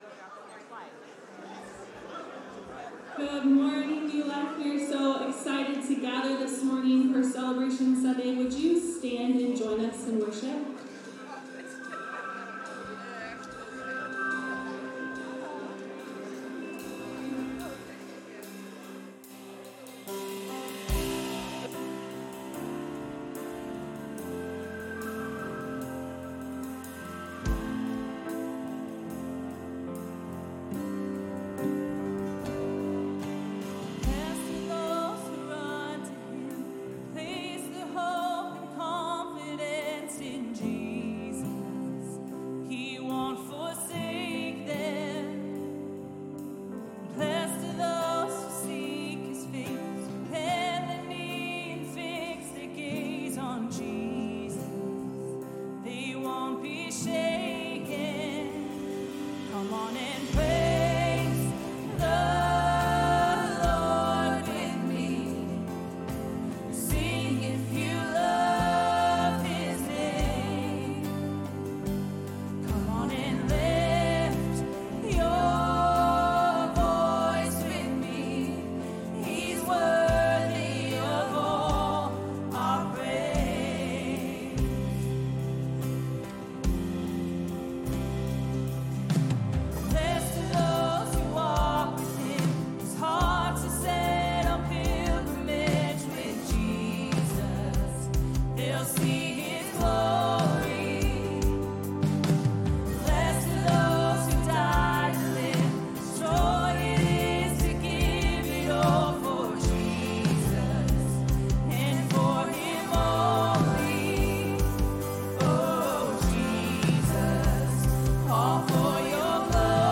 Celebration Sunday 1st service